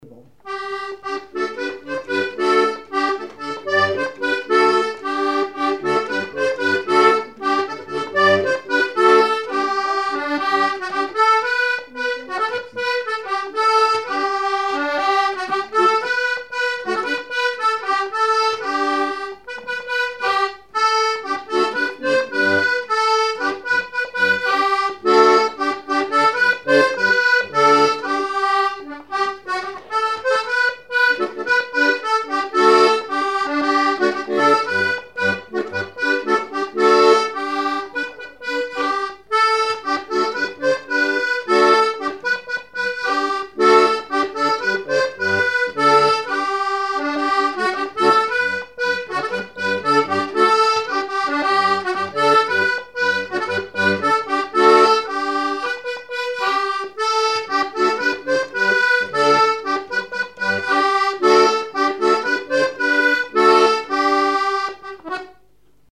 danse : ronde : grand'danse
Genre laisse
Pièce musicale inédite